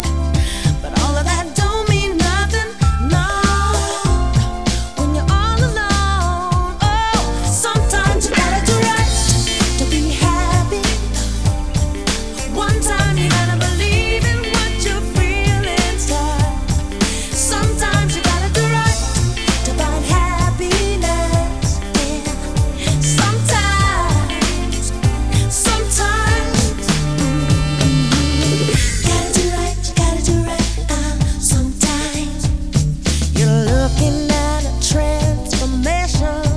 Ultra-cool Acid Jazz - Definitely my best buy of the year!